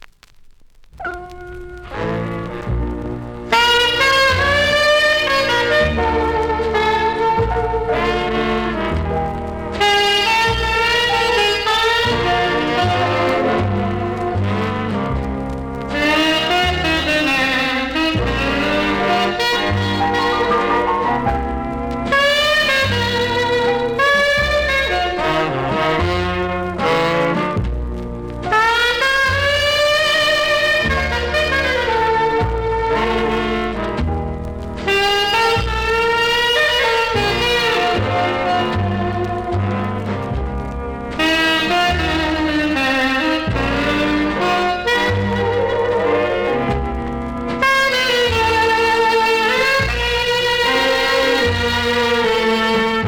INSTRUMENTAL
Vinyl
ジャマイカ盤なのでプレス起因のノイズあります。